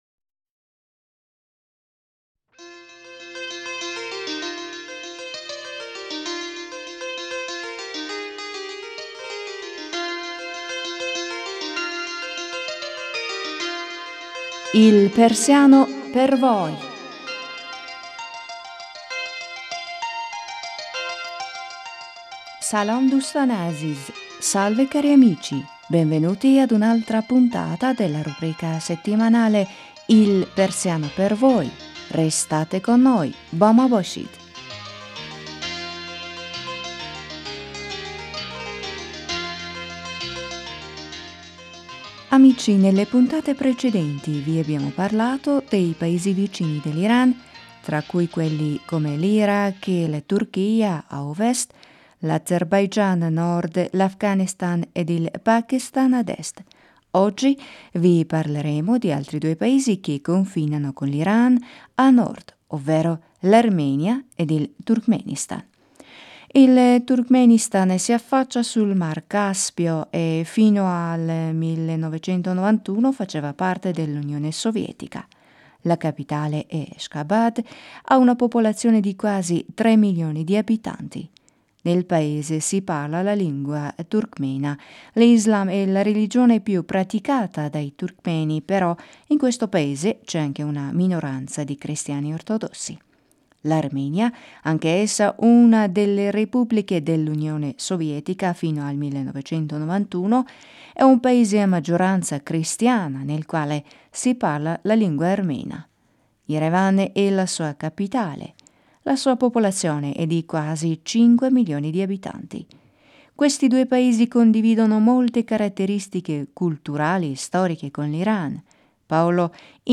Ora insieme a voi ascolteremo la conversazione tra Paolo e Majid sull'Armenia ed il Turkmenistan: